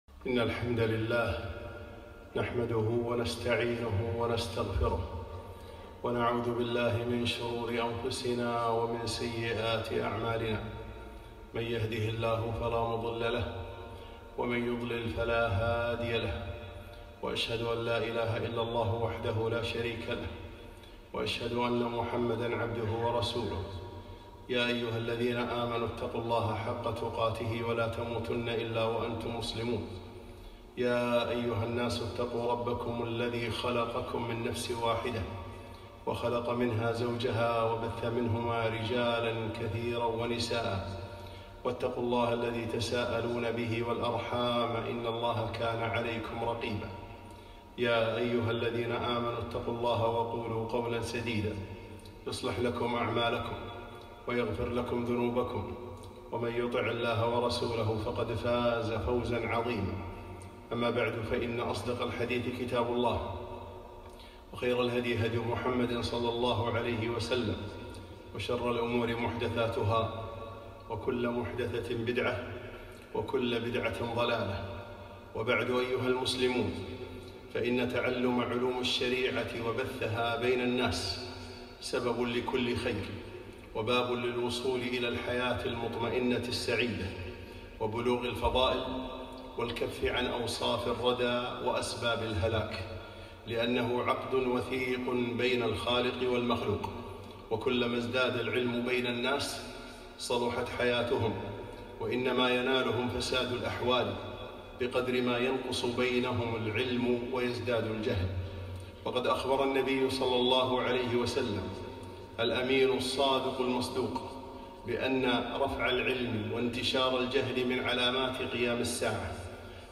خطبة - قبض العلماء